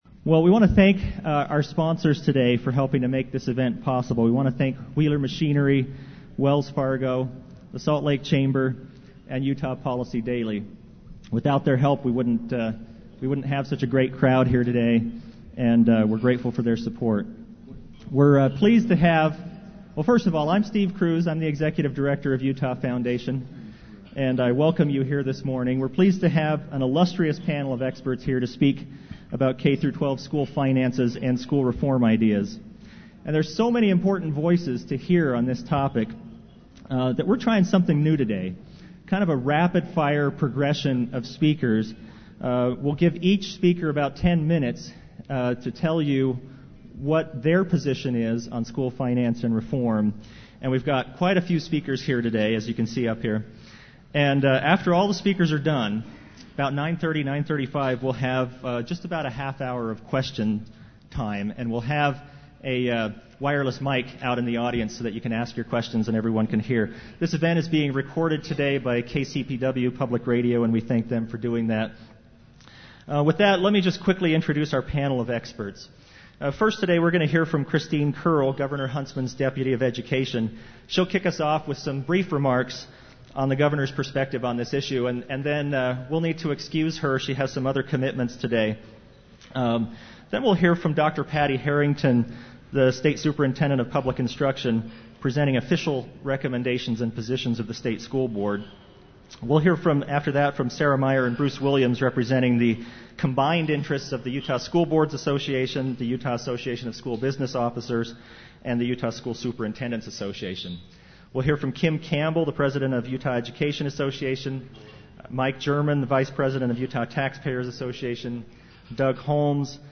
Podcast of 9/7/06 Utah Foundation forum on education finance/reform